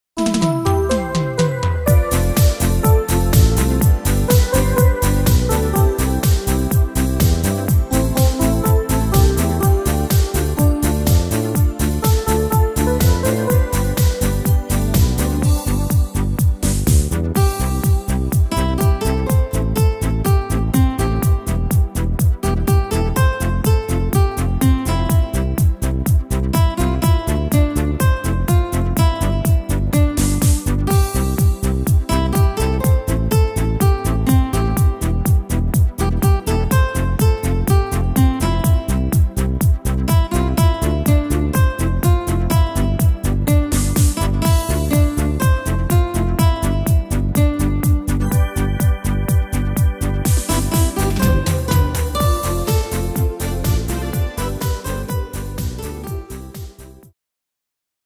Tempo: 124 / Tonart: C-Dur